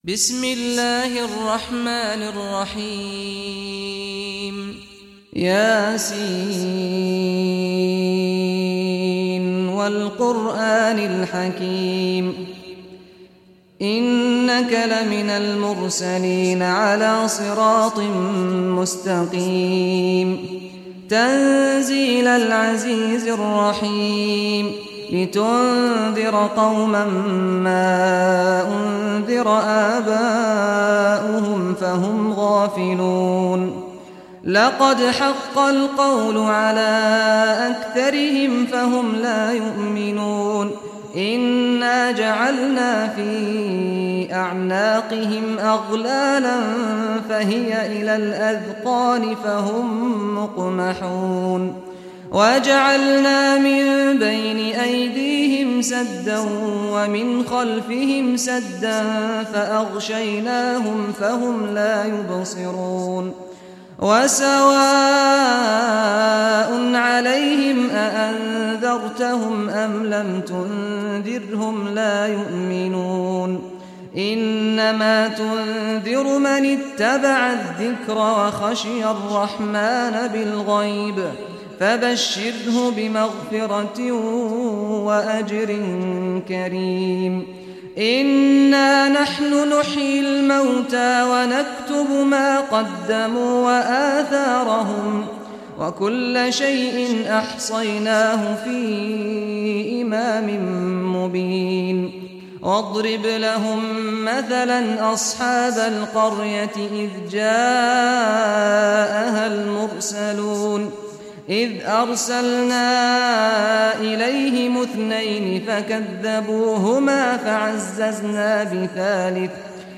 Surah Yaseen Recitation by Sheikh Saad al Ghamdi
Surah Yaseen, listen or play online mp3 tilawat / recitation in Arabic in the beautiful voice of Sheikh Saad al Ghamdi.